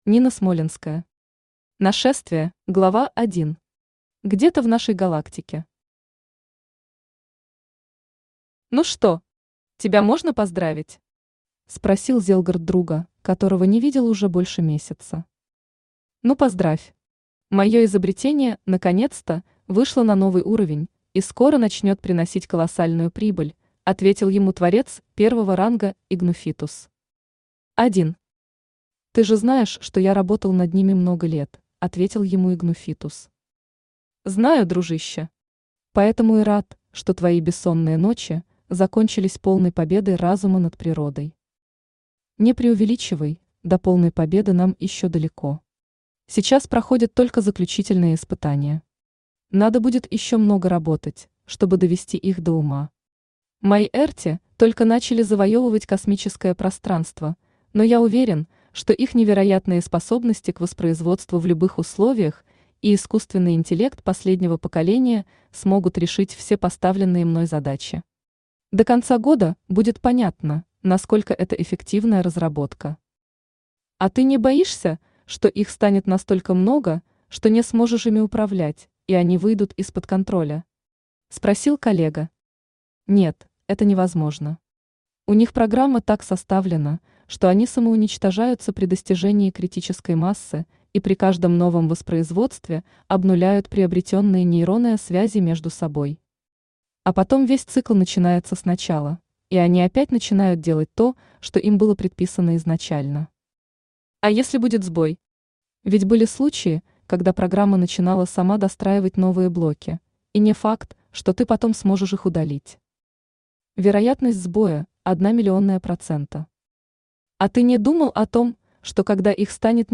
Аудиокнига Нашествие | Библиотека аудиокниг
Aудиокнига Нашествие Автор Нина Смолянская Читает аудиокнигу Авточтец ЛитРес.